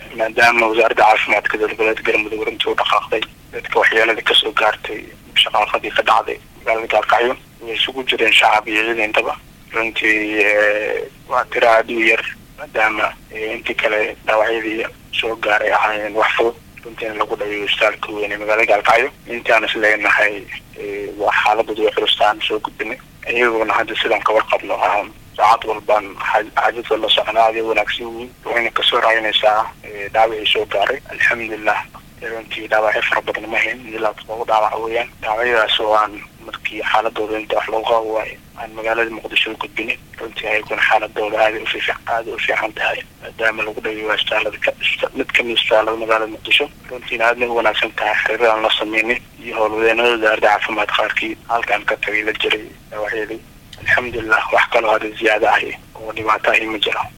Dhagayso wasiir kuxigeen Caafimaadka maamulka Galmudug